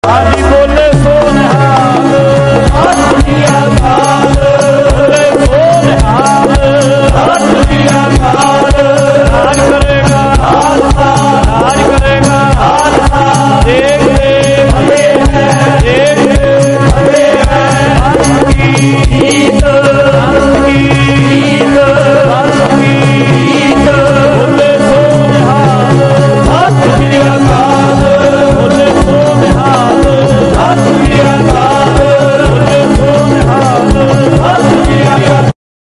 Angg 1236 to 1246 Sehaj Pathh Shri Guru Granth Sahib Punjabi Punjabi